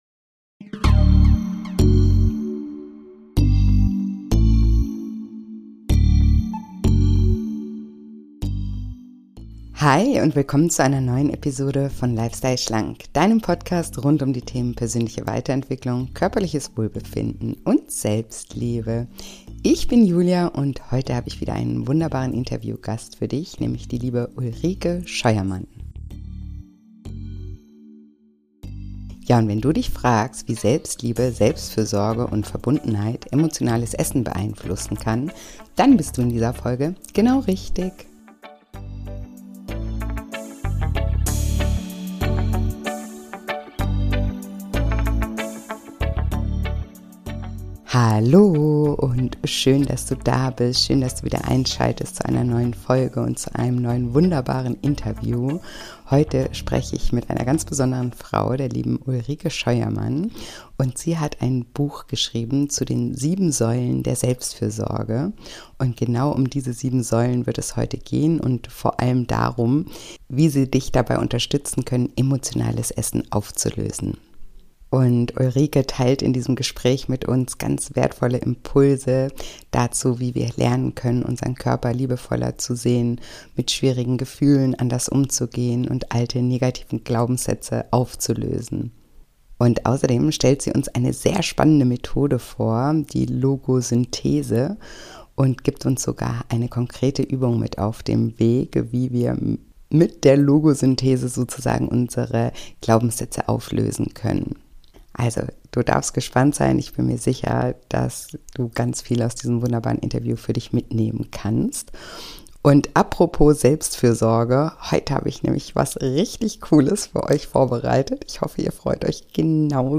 Ein sehr spannendes Interview mit vielen tollen Denkanstößen und konkreten Anleitungen zur Umsetzung, das du auf keinen Fall verpassen solltest.